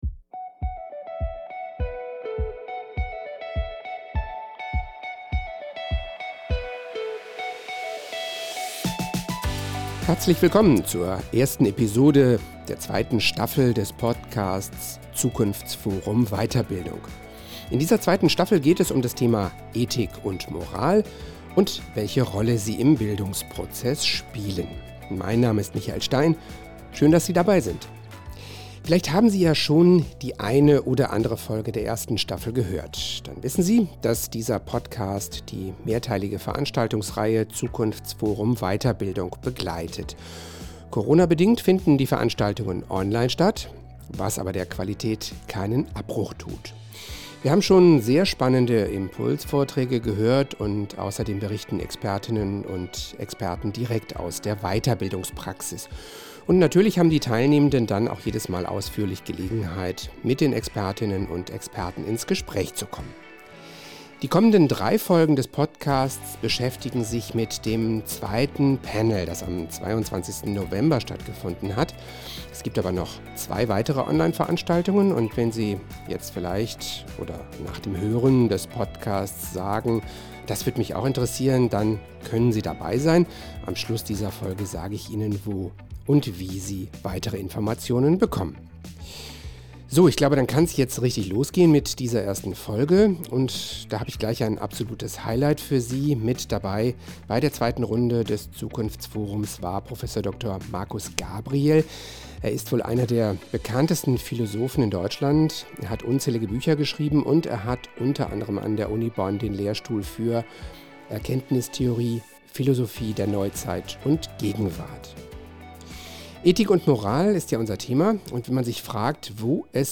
mit dem renommierten Philosophen, Buchautor und Inhaber des Lehrstuhls für Erkenntnistheorie und Philosophie der Neuzeit an der Universität Bonn: Prof. Dr. Markus Gabriel.